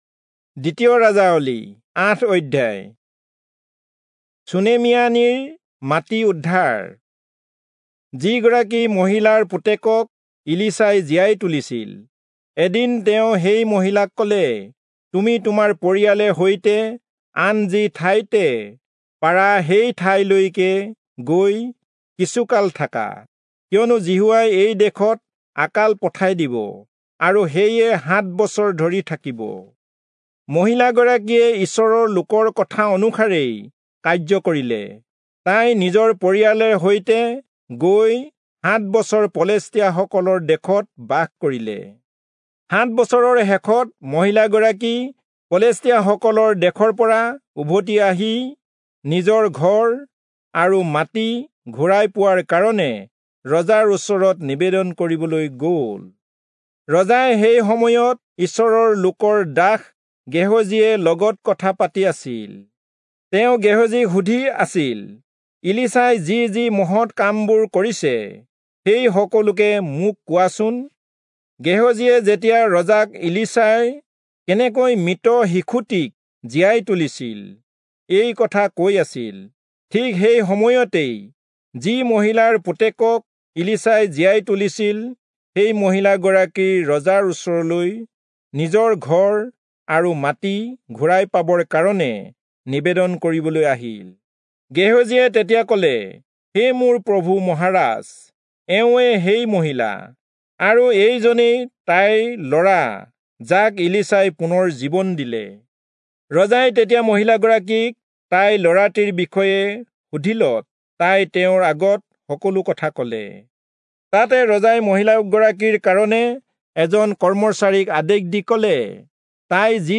Assamese Audio Bible - 2-Kings 6 in Irvor bible version